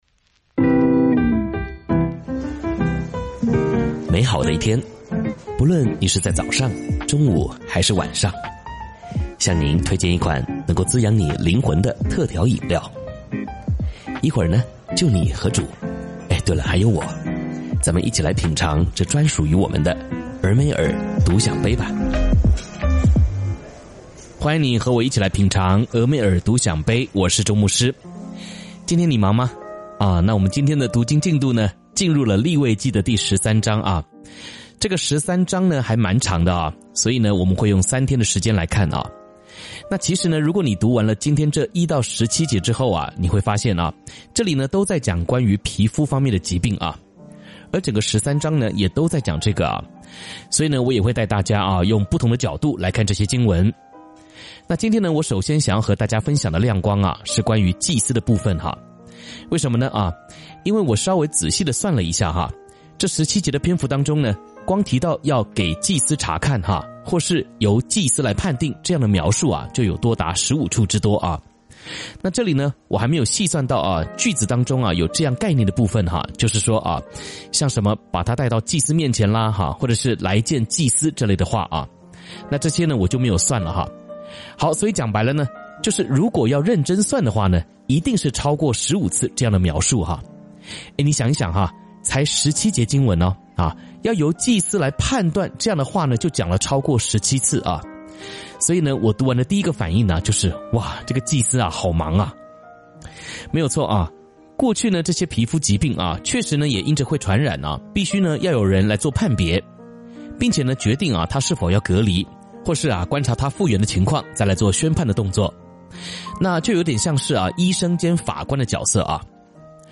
「天父爸爸說話網」是由北美前進教會Forward Church 所製作的多單元基督教靈修音頻節目。